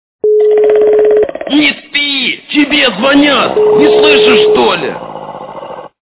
» Звуки » Смешные » Mужской храп - Не спи тебя звонят - не слышишь, что-ли?
При прослушивании Mужской храп - Не спи тебя звонят - не слышишь, что-ли? качество понижено и присутствуют гудки.
Звук Mужской храп - Не спи тебя звонят - не слышишь, что-ли?